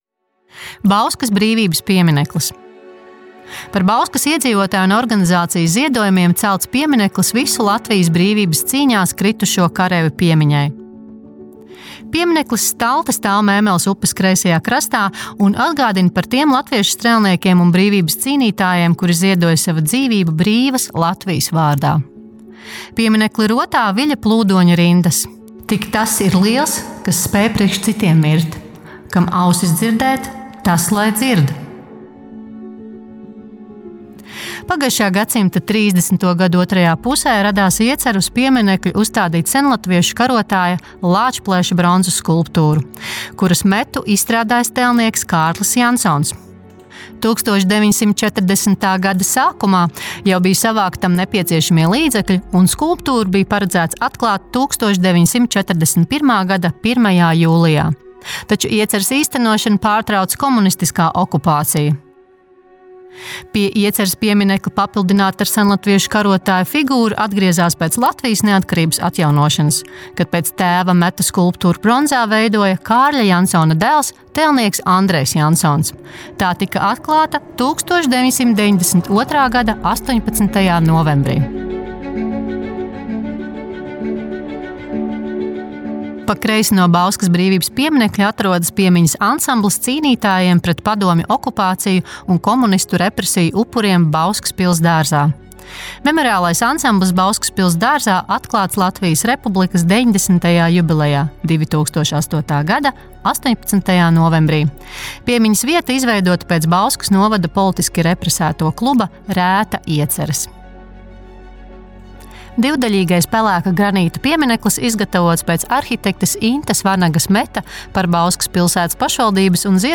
AUDIO STĀSTĪJUMS